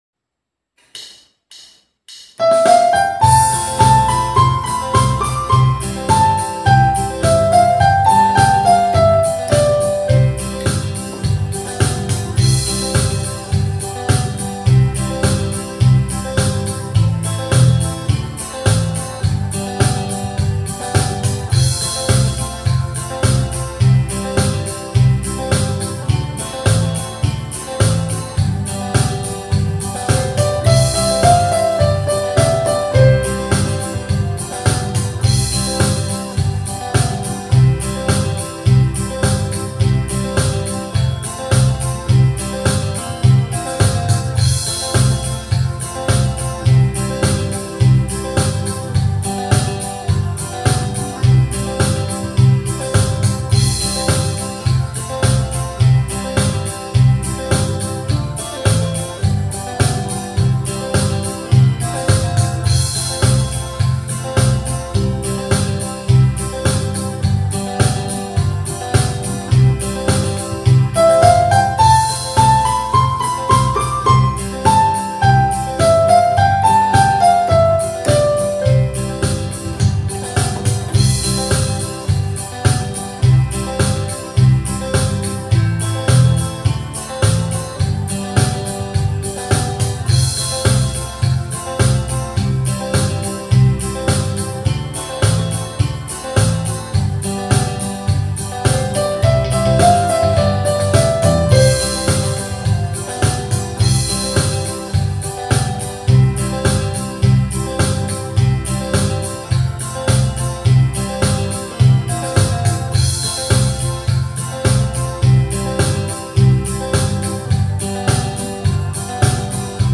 C. Instrumen Lagu:
5.-Master-Instrumen-ok-nian-105ampli.mp3